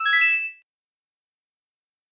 Звук благодарности за вашу подписку